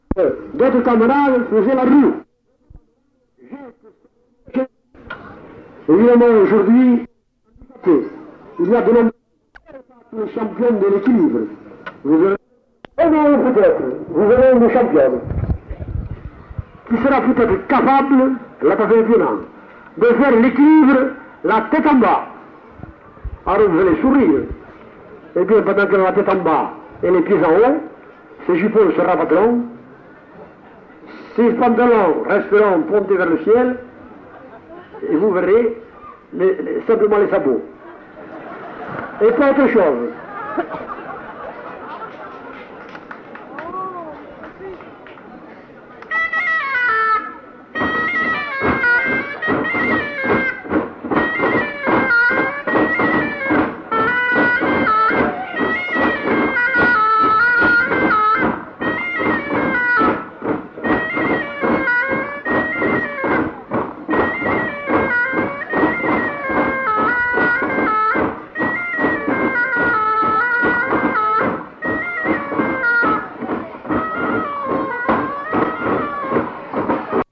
Aire culturelle : Couserans
Lieu : Argelès-Bagnères
Genre : morceau instrumental
Instrument de musique : hautbois
Danse : bourrée d'Ariège